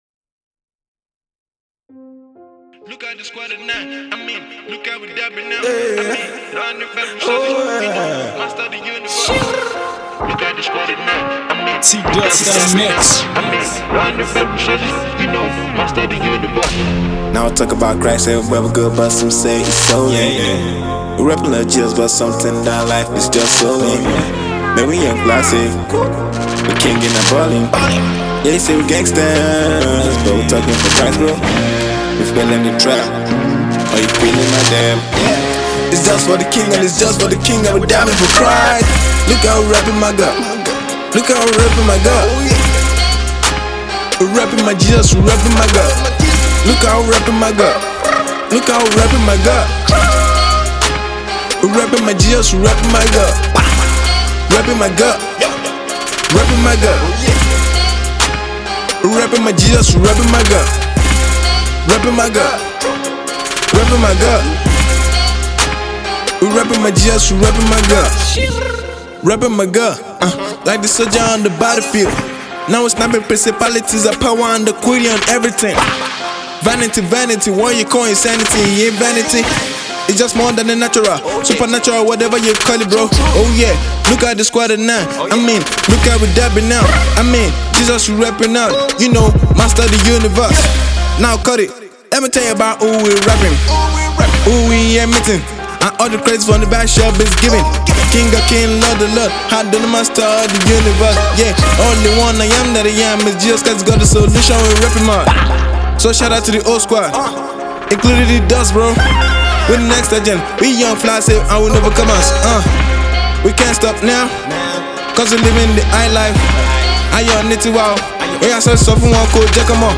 anthem